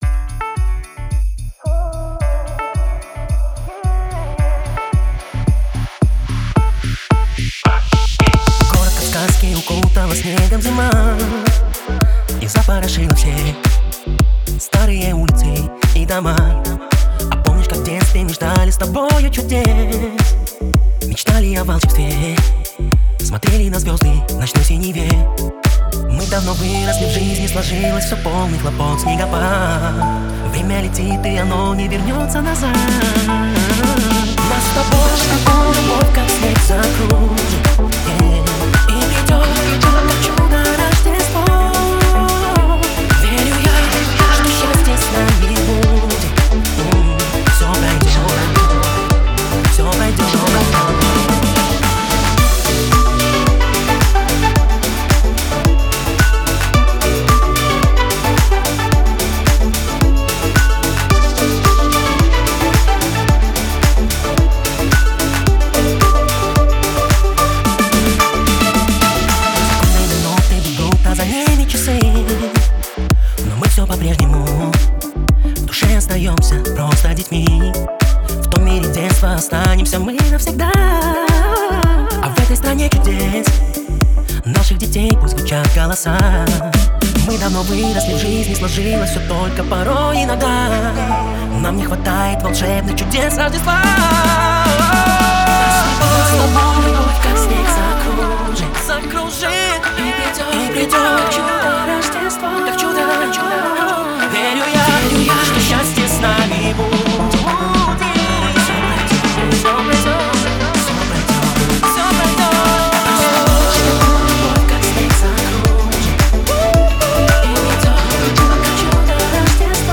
• Качество: 320 kbps, Stereo